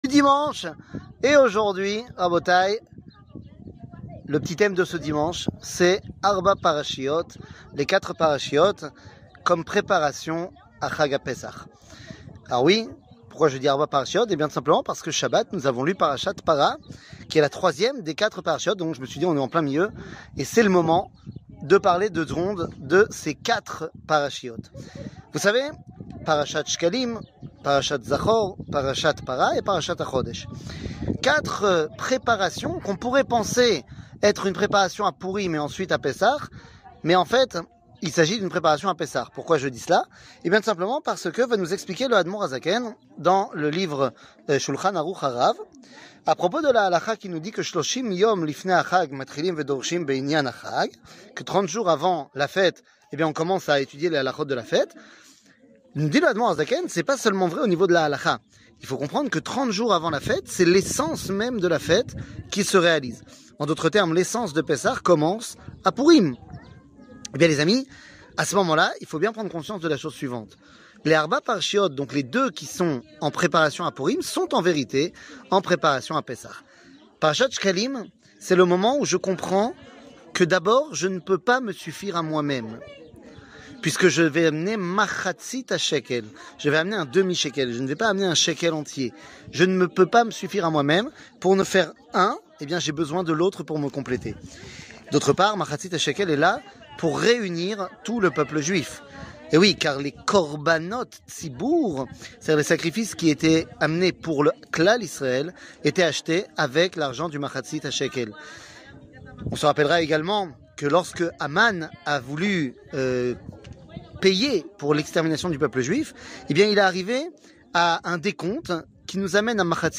Les 4 Parachiot, la preparation a Pessah 00:05:33 Les 4 Parachiot, la preparation a Pessah שיעור מ 12 מרץ 2023 05MIN הורדה בקובץ אודיו MP3 (5.07 Mo) הורדה בקובץ וידאו MP4 (19.88 Mo) TAGS : שיעורים קצרים